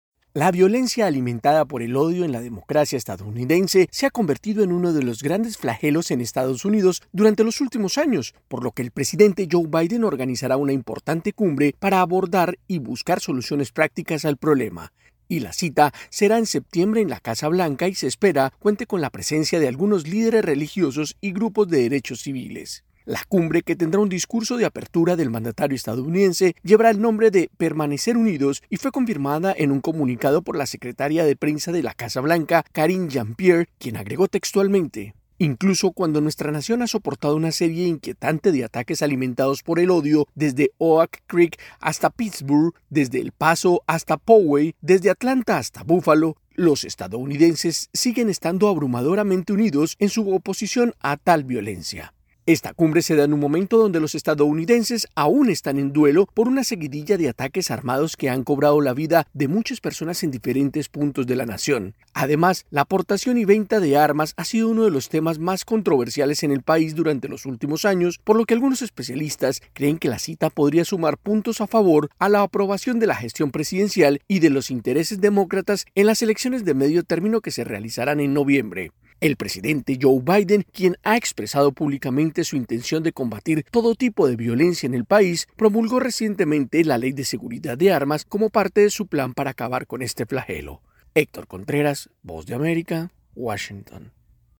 desde la Voz de América en Washington, DC.